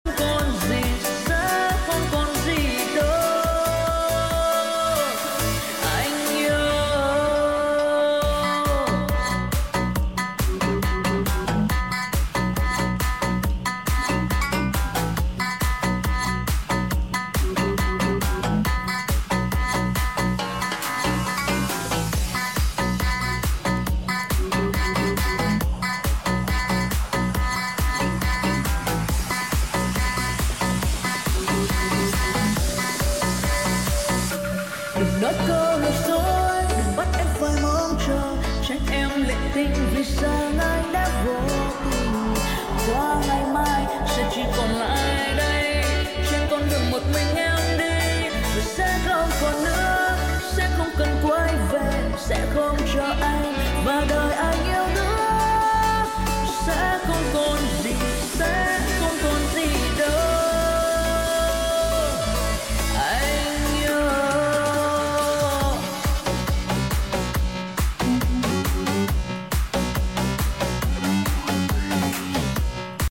hát remix vui năng lượng hơn